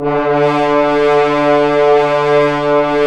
Index of /90_sSampleCDs/Roland LCDP06 Brass Sections/BRS_F.Horns 1/BRS_FHns Ambient
BRS F.HRNS03.wav